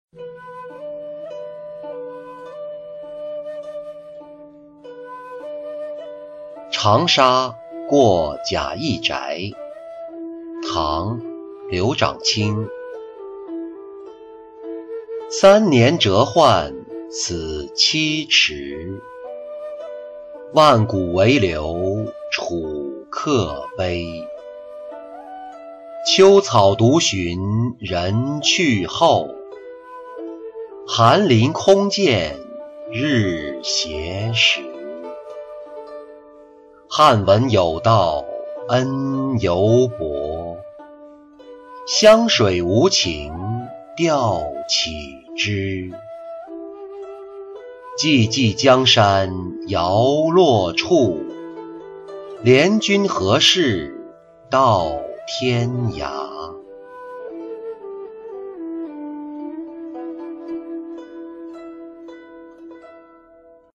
长沙过贾谊宅-音频朗读